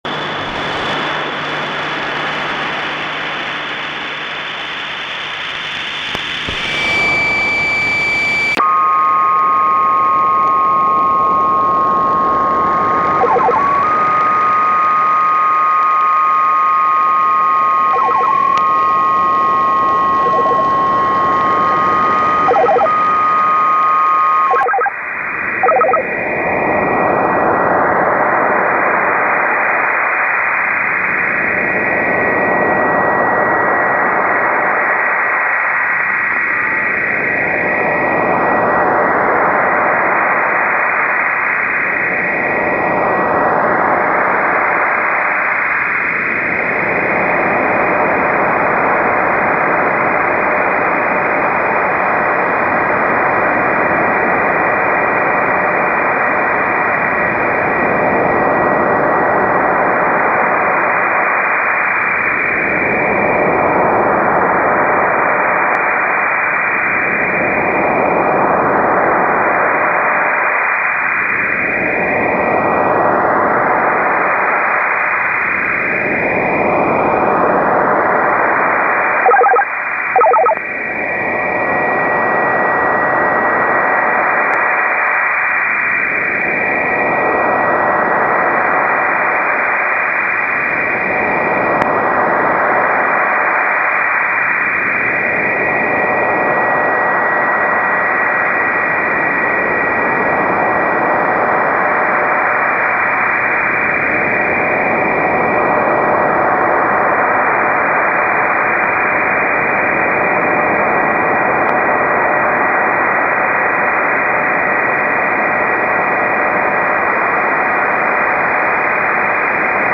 Balades en ondes courtes.